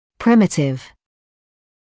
[‘prɪmɪtɪv][‘примитив]примитивный, простой; первобытный